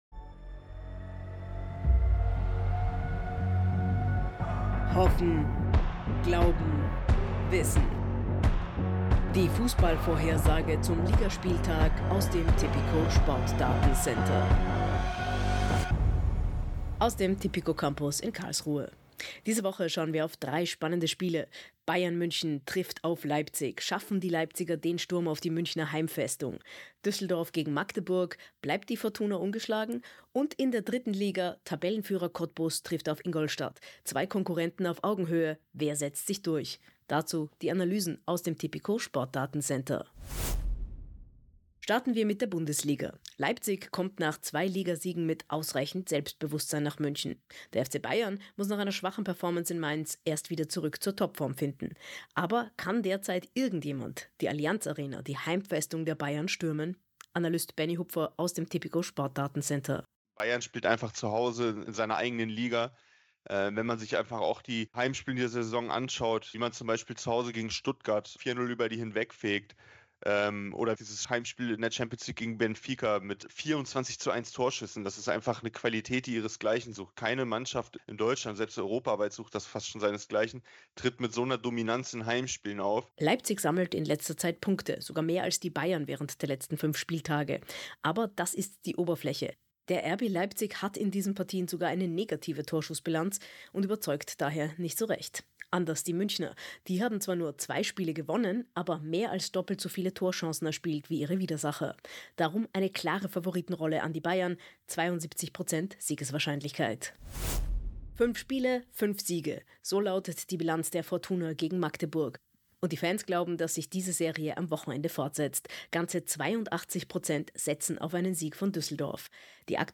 • Newscast
Die Pressemeldung im Audio-Newscast: